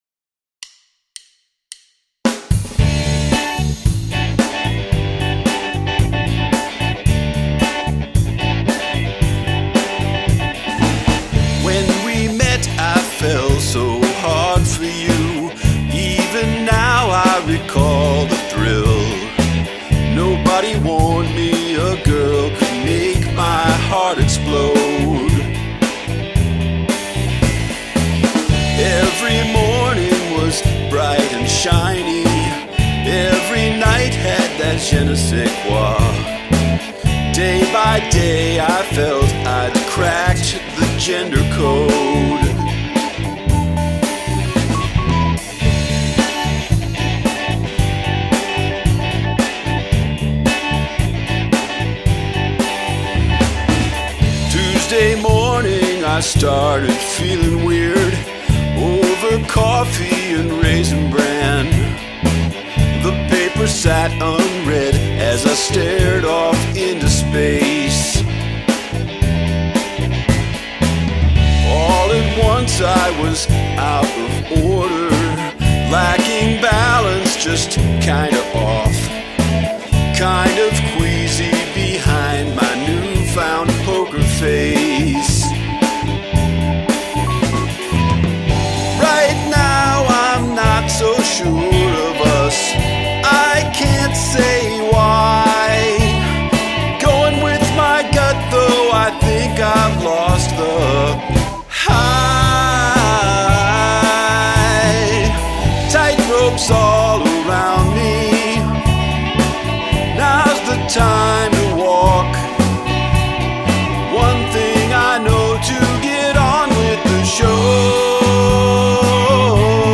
A solid structure. Verse,Verse, Chorus, bridge, Chorus.
Love the mix! Can't really complain about a thing.
Sorry about the Morse Code crack, it's a really catchy hook!